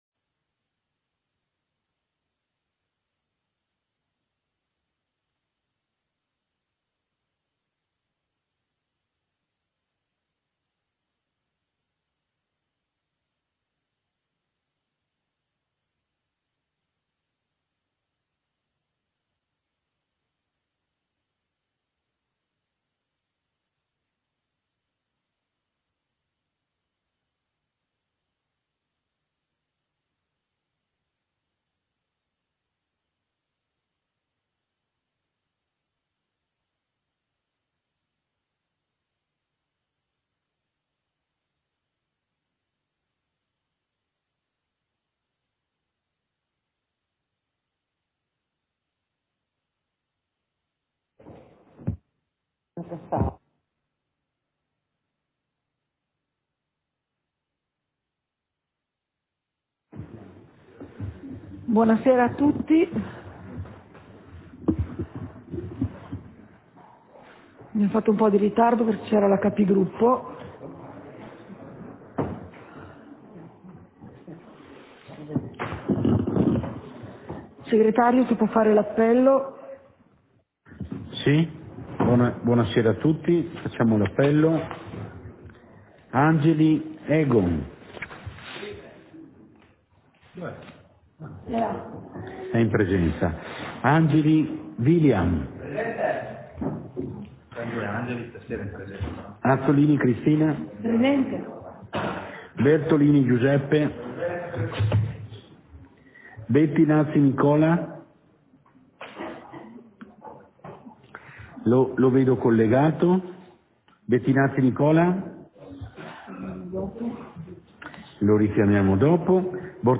Seduta del consiglio comunale - 26.04.2022